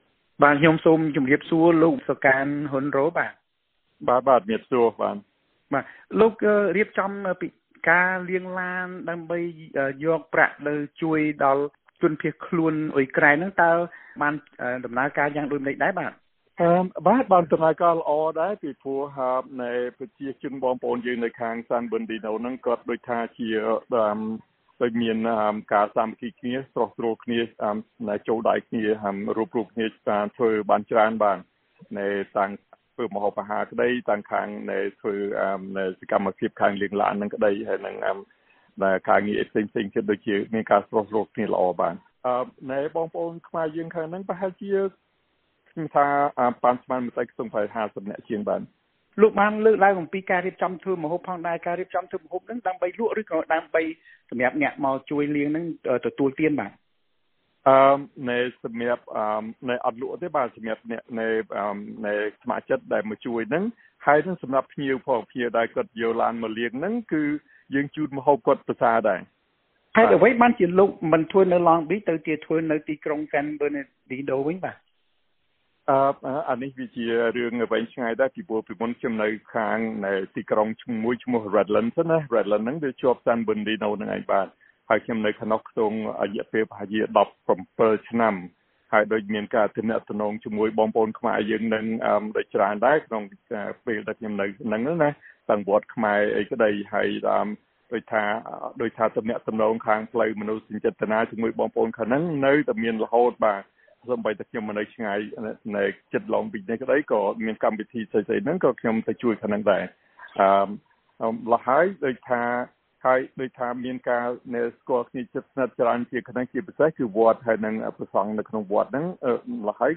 បទសម្ភាសន៍ VOA៖ អតីតជនភៀសខ្លួនខ្មែរលាងរថយន្តដើម្បីអង្គាសប្រាក់ជួយជនភៀសខ្លួនអ៊ុយក្រែន